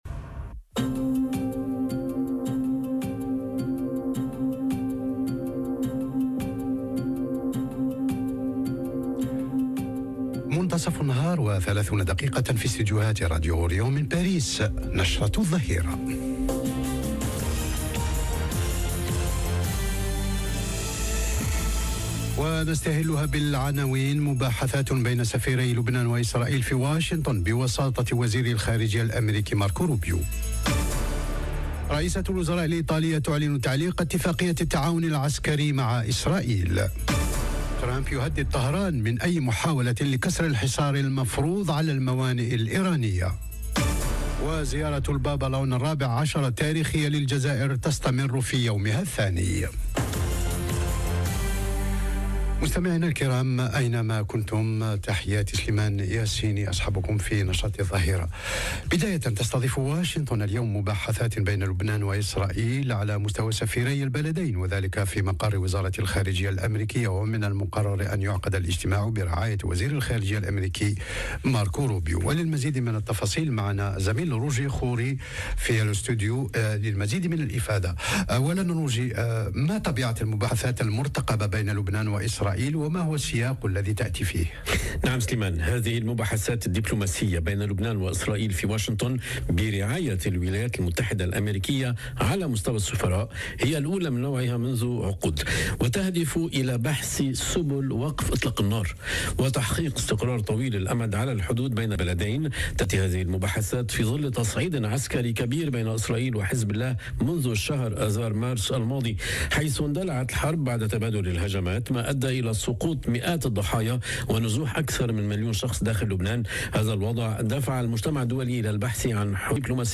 نشرة الظهيرة..